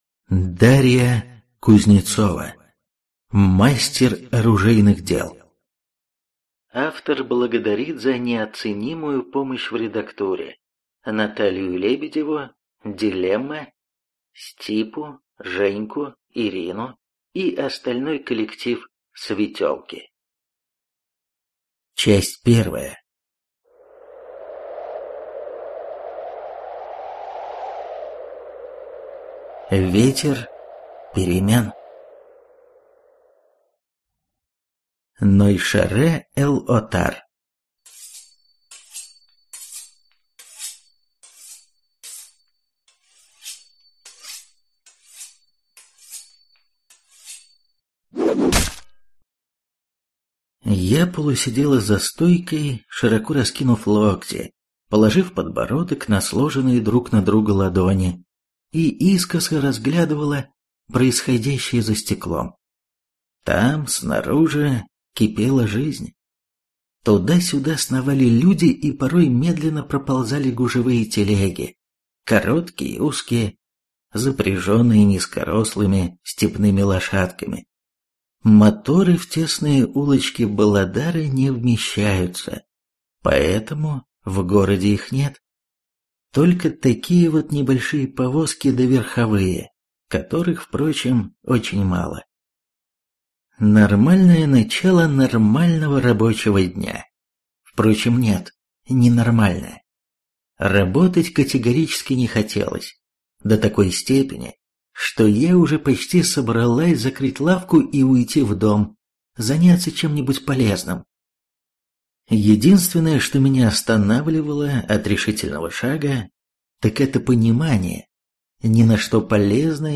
Скачать, слушать онлайн аудиокнигу Мастер оружейных дел из жанра Фэнтези